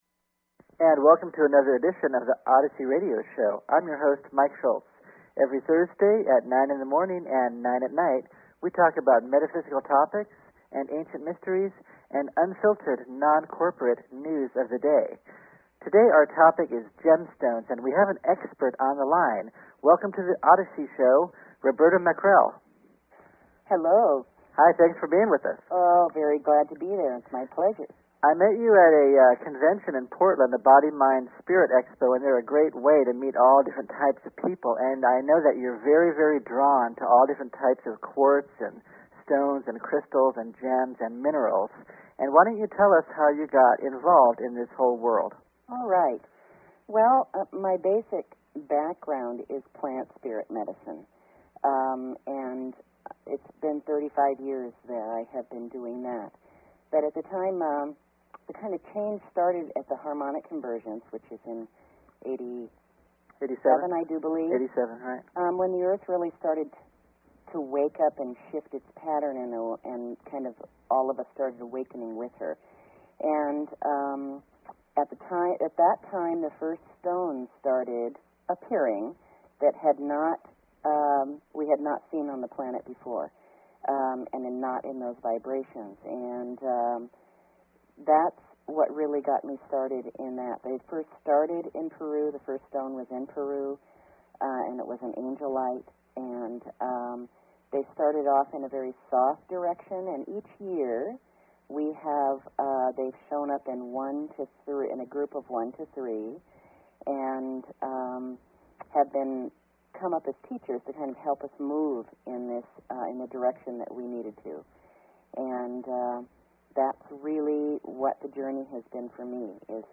Talk Show Episode, Audio Podcast, Odyssey and Courtesy of BBS Radio on , show guests , about , categorized as
Courtesy of BBS Radio